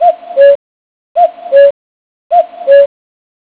Kuckucksuhr cockoo light Karlsson
Hier klicken und hören wie die Zeit klingt.